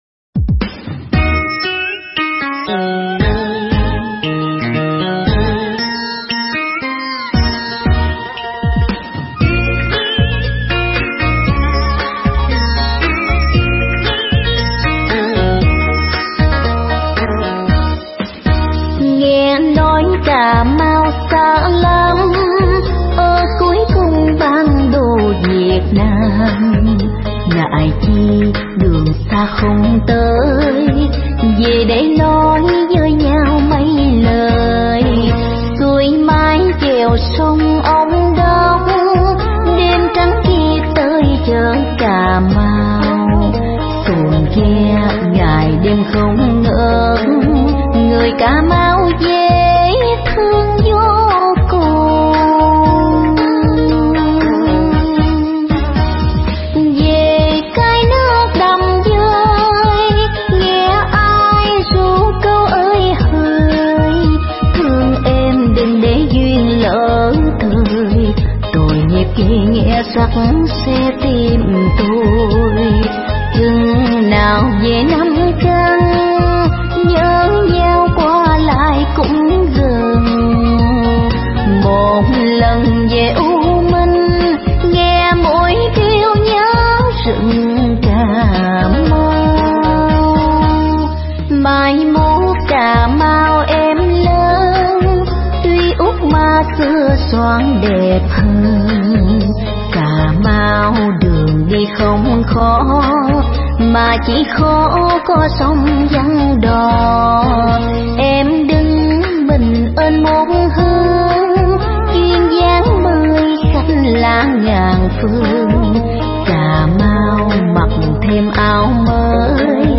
Nhạc Dân Tộc Remix